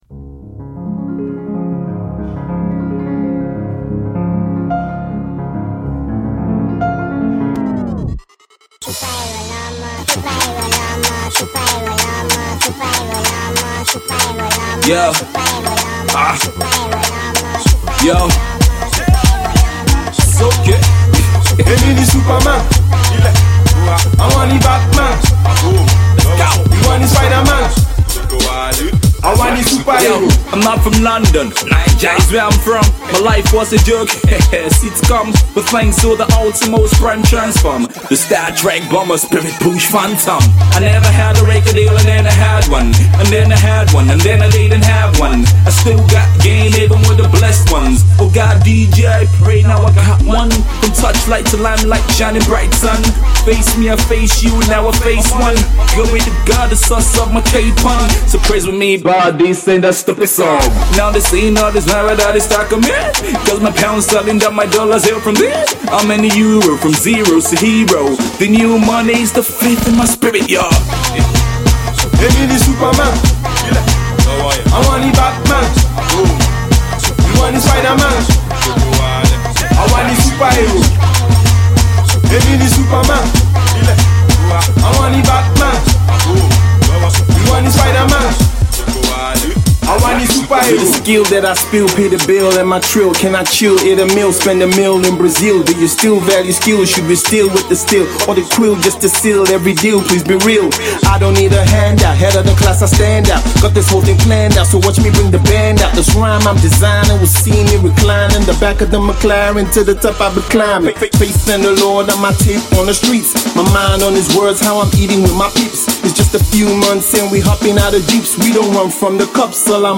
rap duo
Rap Collective